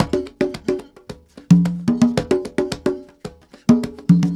CongaGroove-mono.wav